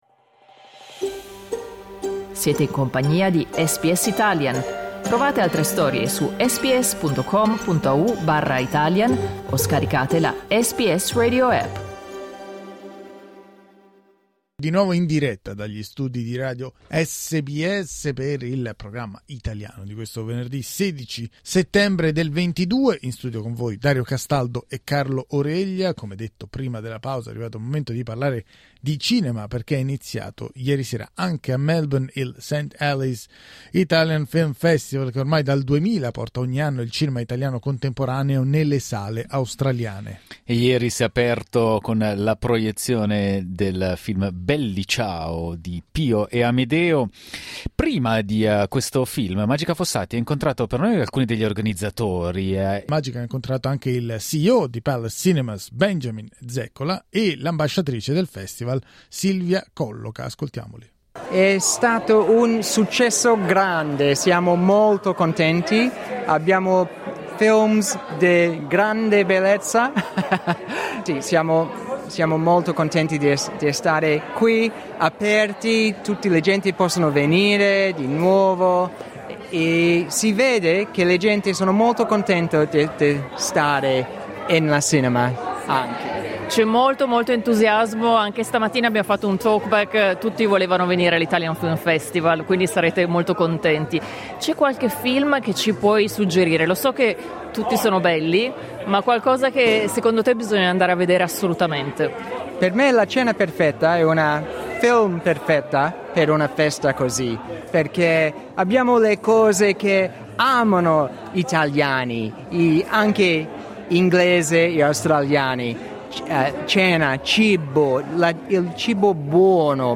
È iniziato giovedì sera anche a Melbourne il St Ali Italian Film Festival, che fin dal 2000 porta ogni anno il cinema italiano contemporaneo nelle sale australiane.